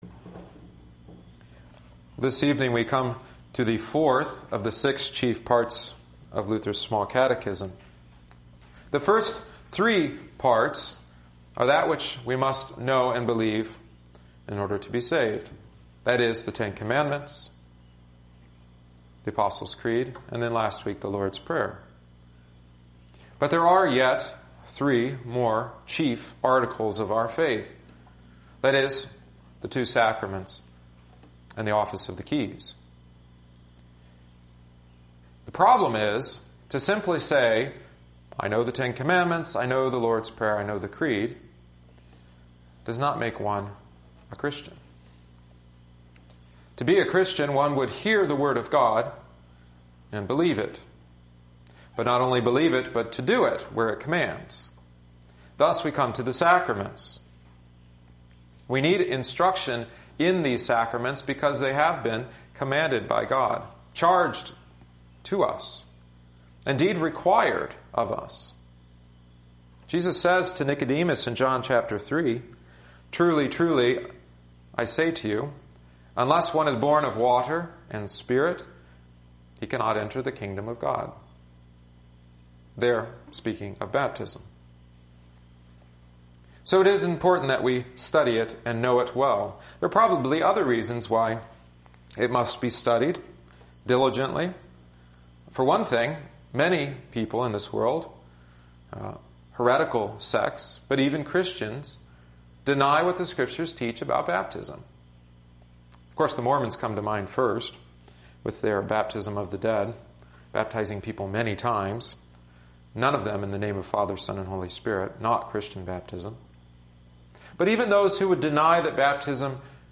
Psalm 4 (antiphon: v. 8) Office Hymn: 423 Jesus, Refuge of the Weary OT: 2 Kings 5:1-14; Ep: Acts 2:36-47; Gos: Mt 28:16-20 Catechetical Hymn: 596 All Christians Who Have Been Baptized